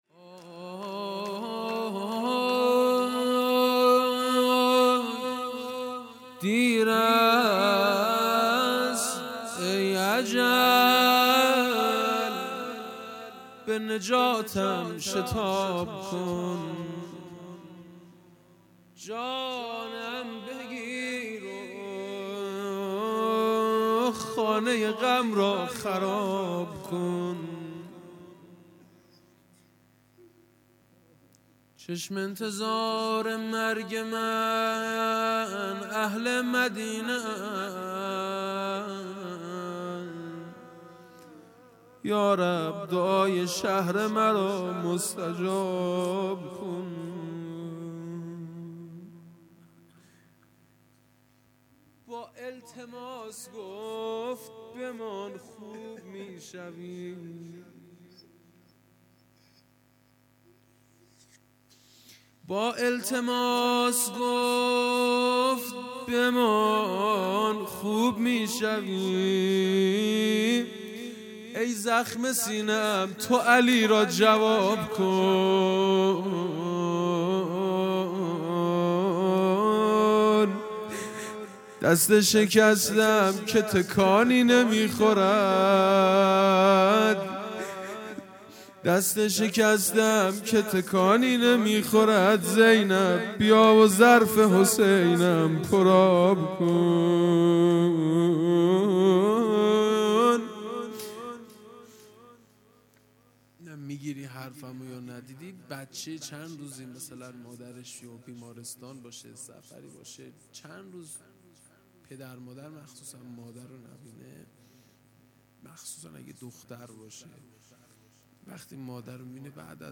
هیئت دانشجویی فاطمیون دانشگاه یزد
روضه پایانی
شهادت حضرت زهرا (س) ۱۹ بهمن ۹۷